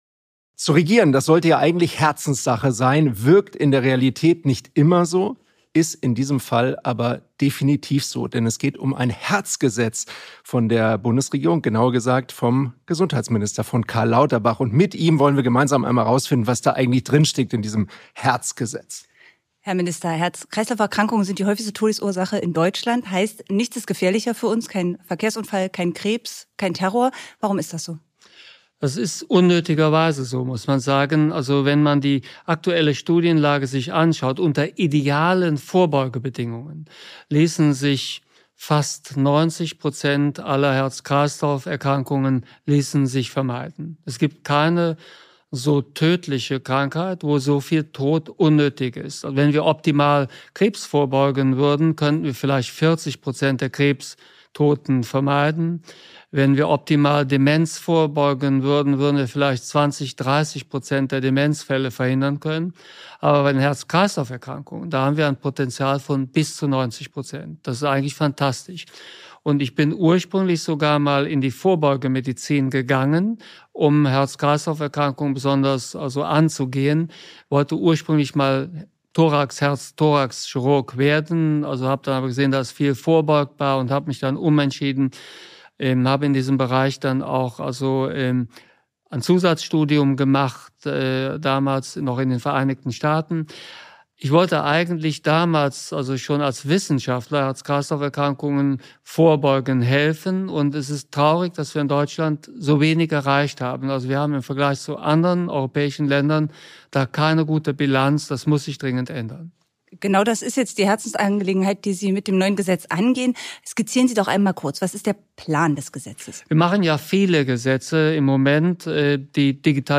Der Bundesgesundheitsminister beantwortet die Frage, wie genau er mit dem geplanten Herz-Gesetz die Zahl der Herz-Toten in Deutschland drastisch senken möchte.